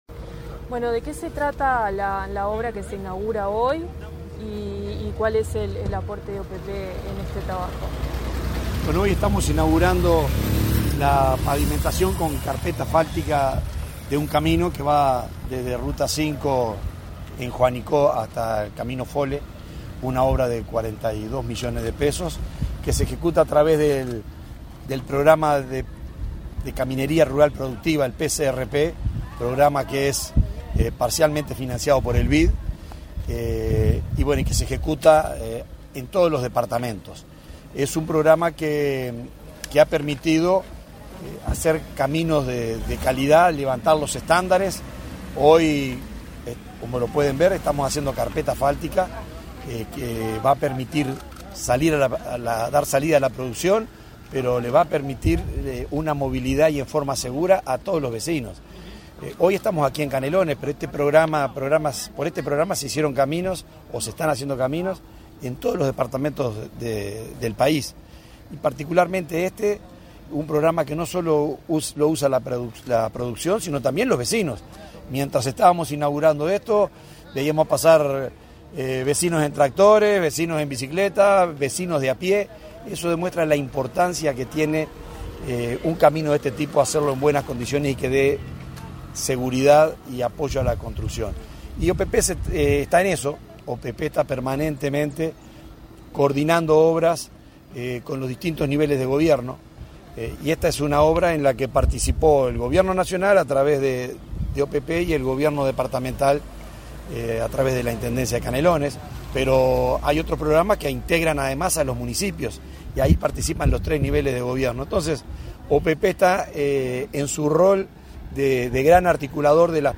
Declaraciones del subdirector de la OPP, Benjamín Irazábal
Declaraciones del subdirector de la OPP, Benjamín Irazábal 16/03/2022 Compartir Facebook X Copiar enlace WhatsApp LinkedIn Tras participar en la inauguración de obras en el camino Al Gigante, en Canelones, este 16 de marzo, el subdirector de la Oficina de Planeamiento y Presupuesto (OPP) efectuó declaraciones a Comunicación Presidencial.